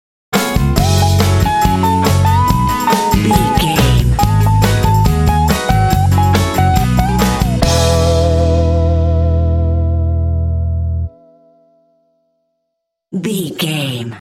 Uplifting
Ionian/Major
funky
electric guitar
bass guitar
drums
percussion
electric organ
electric piano
Funk
jazz
blues